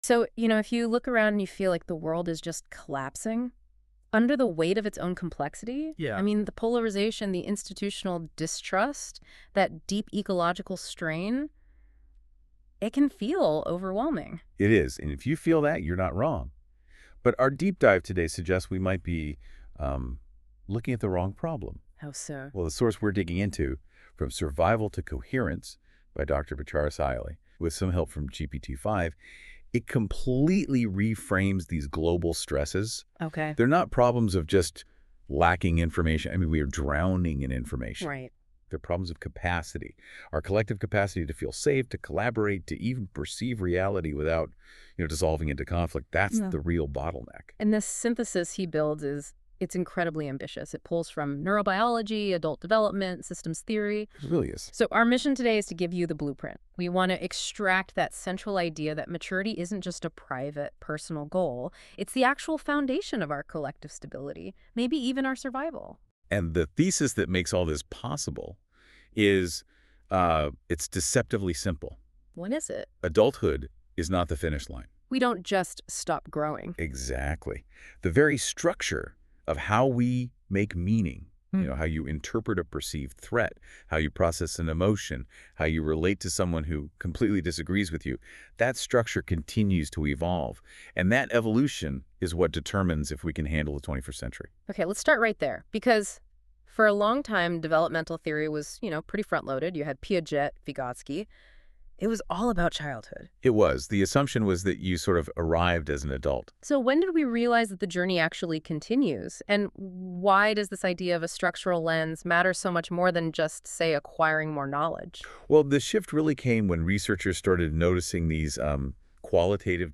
Deep Dive Audio Overview